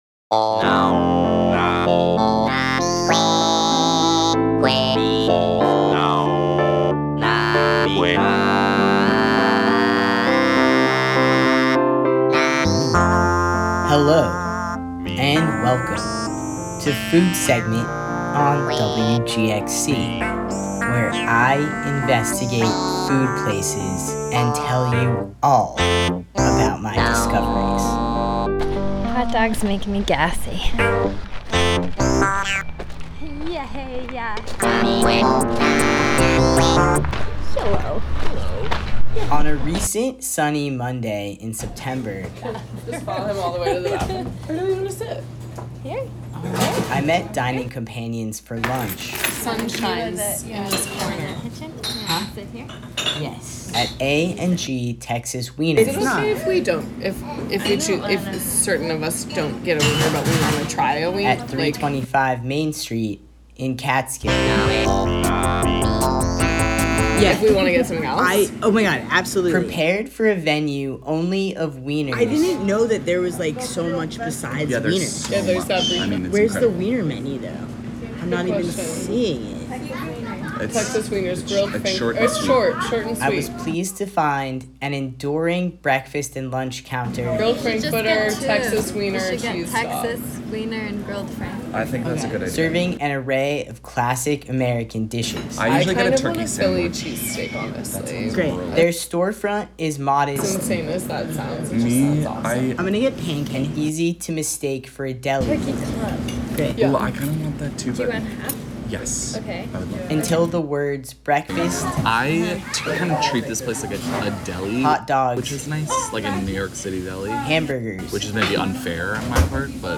They get more than they bargained for, having bargained for weiners. A long discussion ensues about where the turkey should go on a club sandwich, mushrooms are left off of a philly cheesesteak, and ketchup is applied, but when pancakes that were ordered do not arrive, they must be taken to go and assessed off premises.